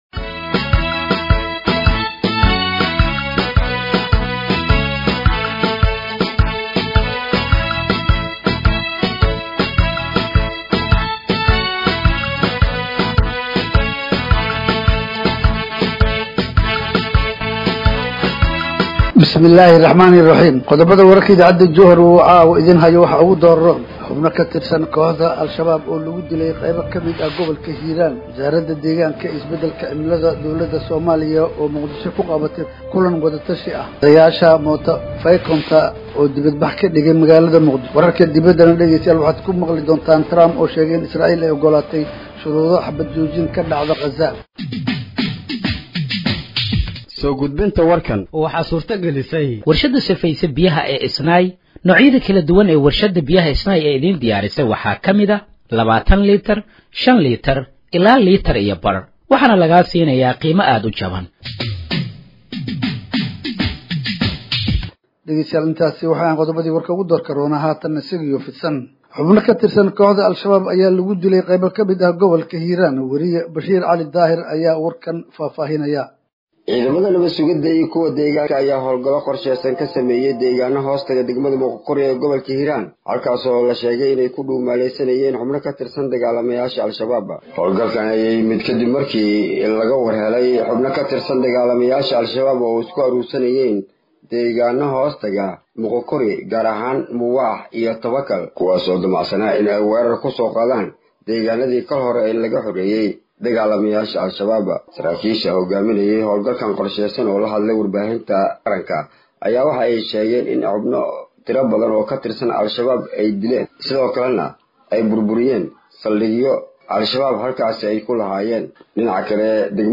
Dhageeyso Warka Habeenimo ee Radiojowhar 02/07/2025
Halkaan Hoose ka Dhageeyso Warka Habeenimo ee Radiojowhar